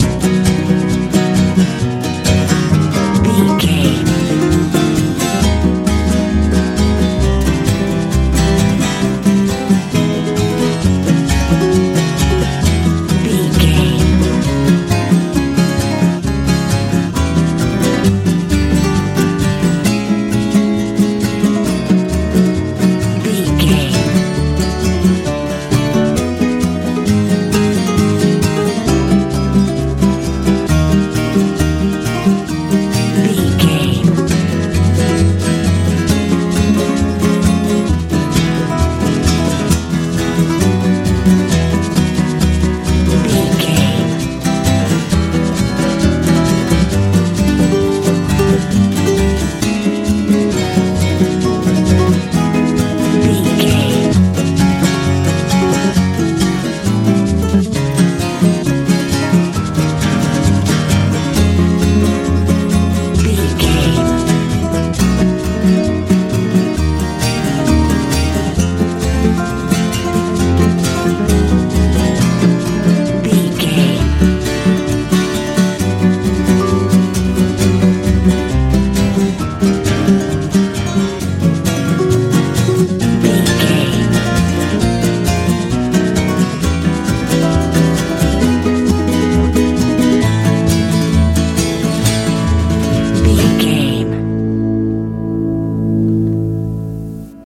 country pop feel
Ionian/Major
driving
groovy
acoustic guitar
electric guitar
bass guitar
drums
sweet
southern
relaxed